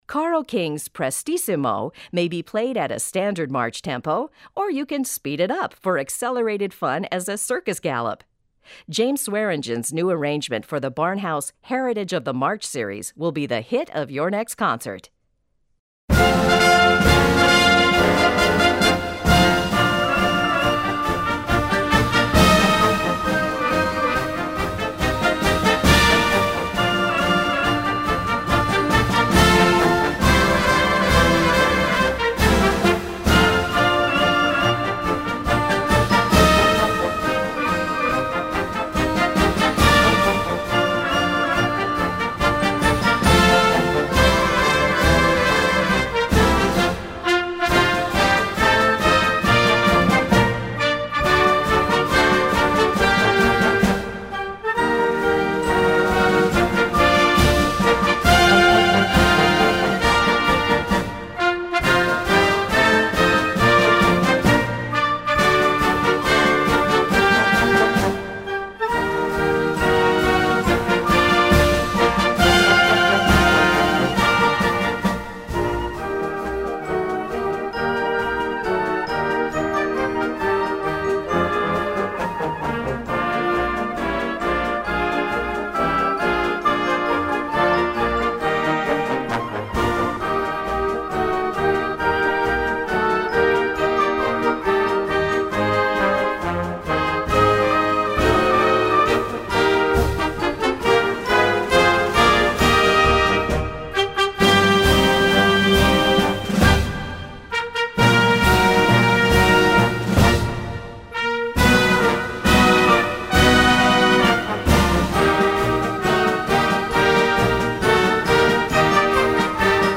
Besetzung: Blasorchester
Einfach mitreissend!